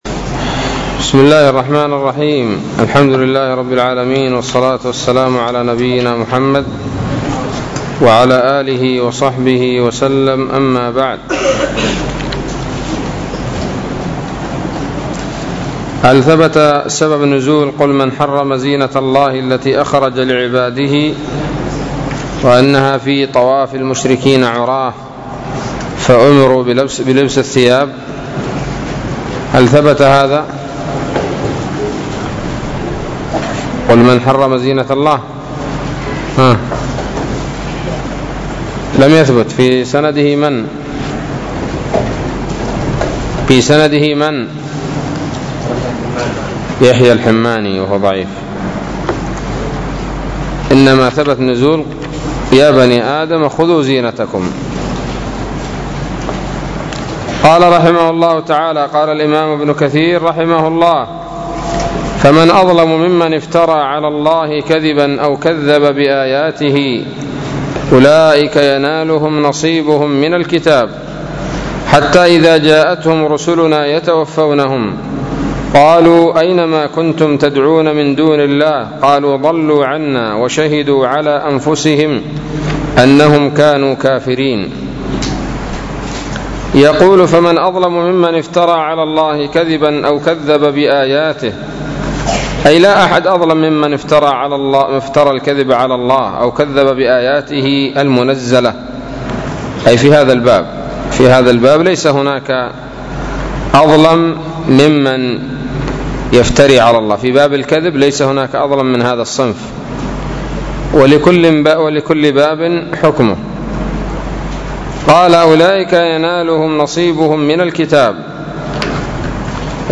الدرس الرابع عشر من سورة الأعراف من تفسير ابن كثير رحمه الله تعالى